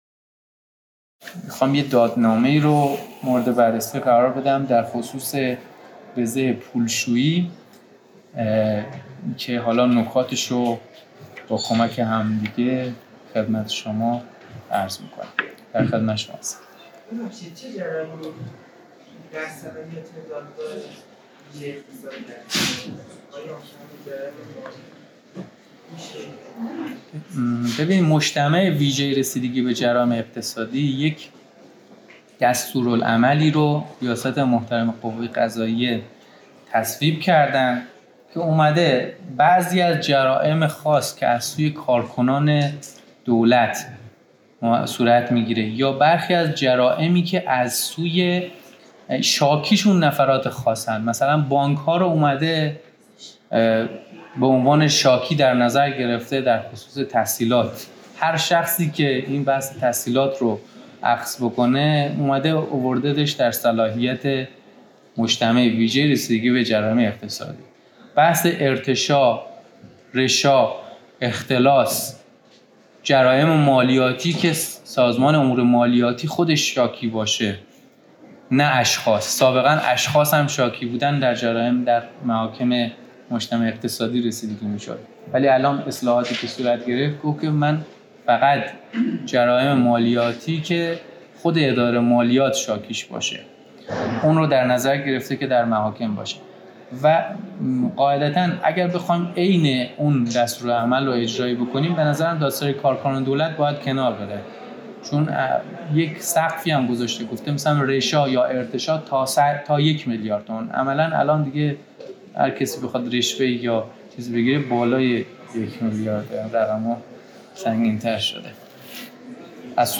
تحلیل تشریفات رسیدگی به جرائم اقتصادی با بررسی پرونده‌های واقعی و نقش دادسرا و دادگاه‌های تخصصی، در گفت‌وگو